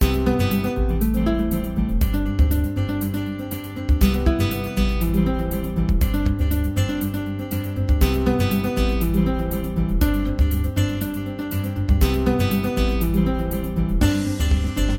Major Pentatonic Mode